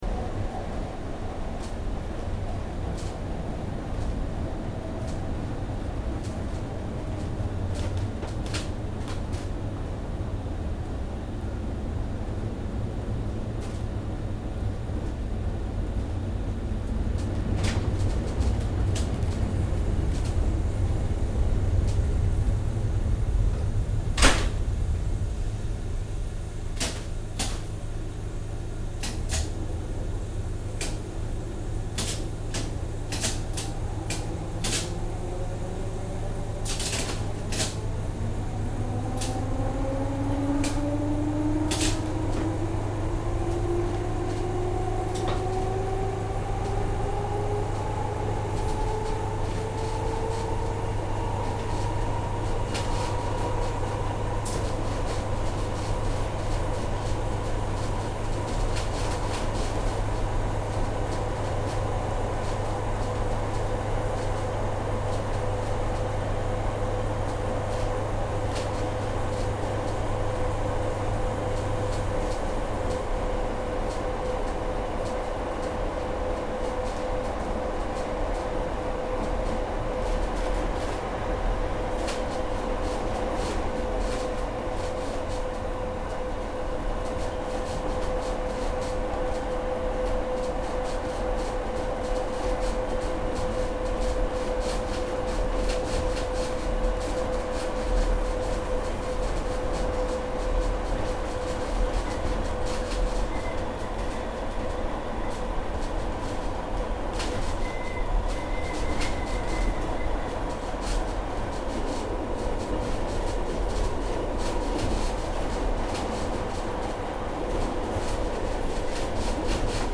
Roslagsbanan, train from 1934 without guide (2)